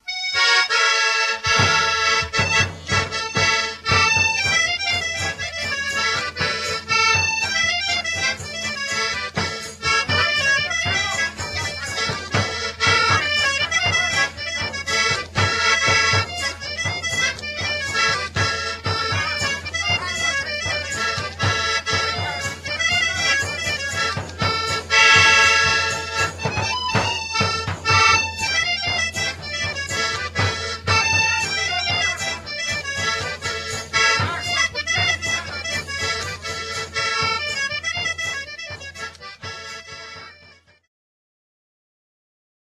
Oberek opoczyński (Ligęzów, 1989)
harmonia 3-rzędowa, 24-basowa "Kwatek"
bębenek